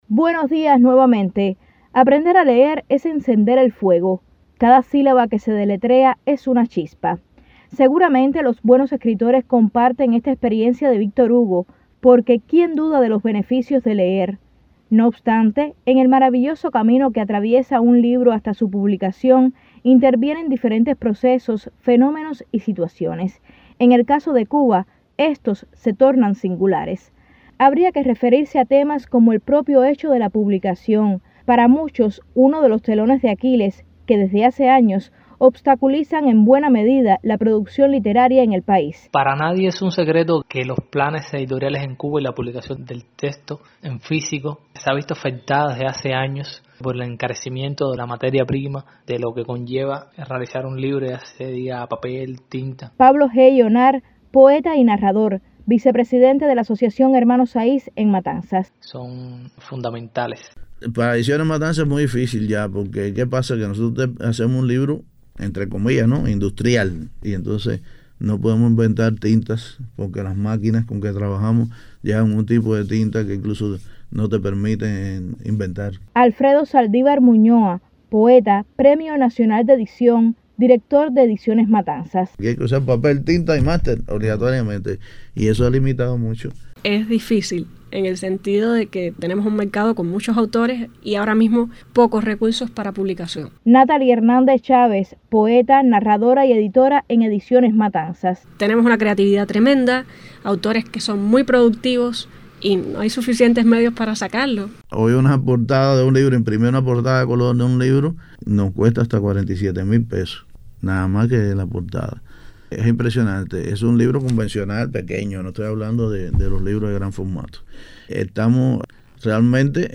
Es la literatura, específicamente algunos temas y procesos que definen, acompañan, reafirman, sostienen, impulsan o limitan el óptimo desarrollo las letras cubanas, el contenido de una serie de reportajes que le compartimos por estos días.
Estas son las opiniones de algunos escritores matanceros.